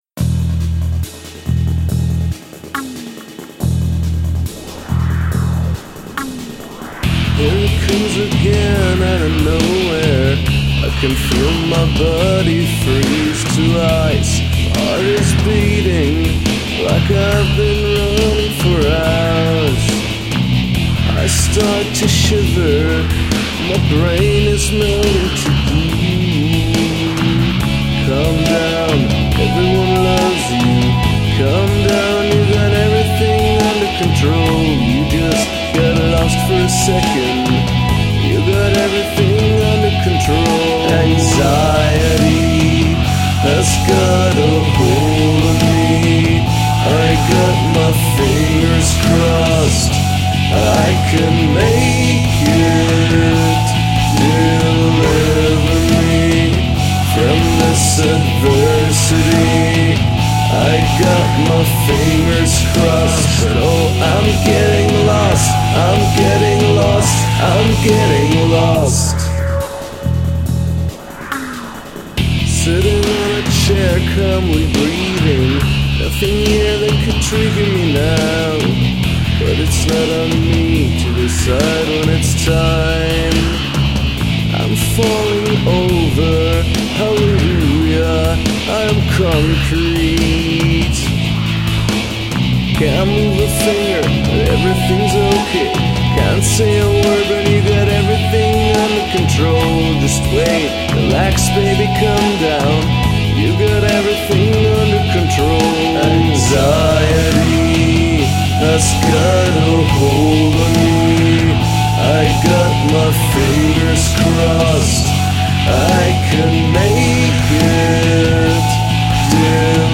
include a section of backwards singing or instrumentation
Good chorus on this one, it even has a counter-melody.